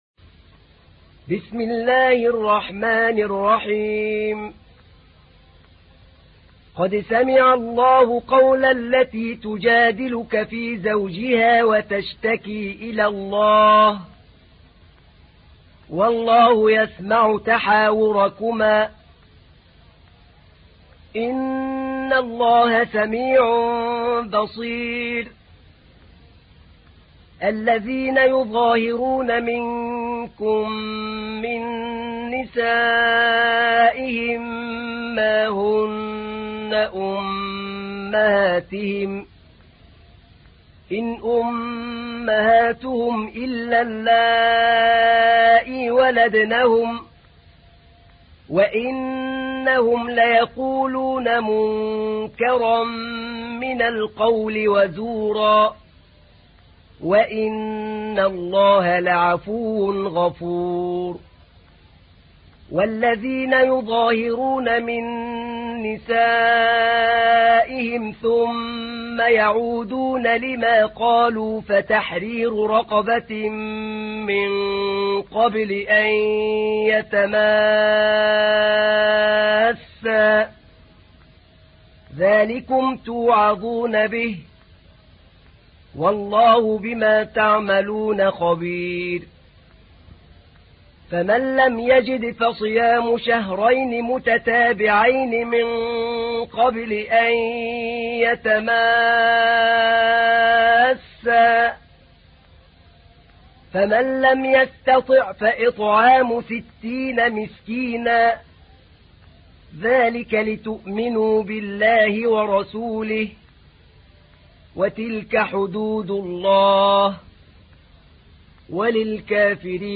تحميل : 58. سورة المجادلة / القارئ أحمد نعينع / القرآن الكريم / موقع يا حسين